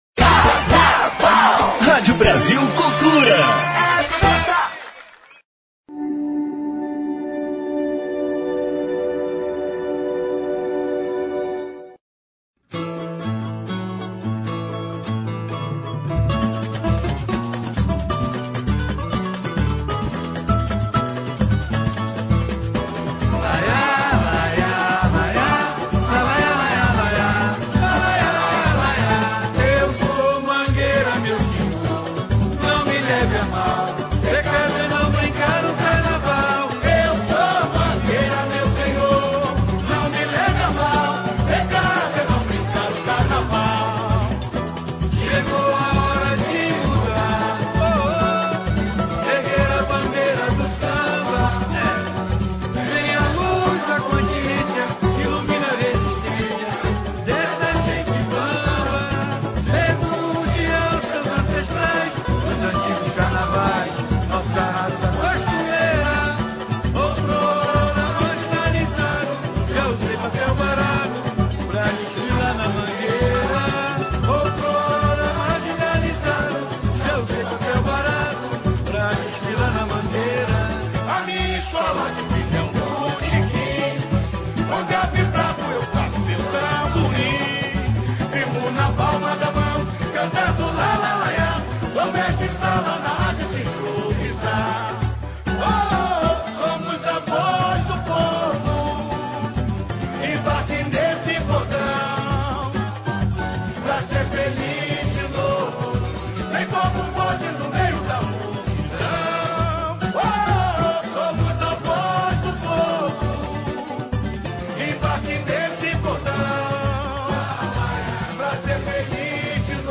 samba enredo irônico